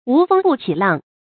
注音：ㄨˊ ㄈㄥ ㄅㄨˋ ㄑㄧˇ ㄌㄤˋ
讀音讀法：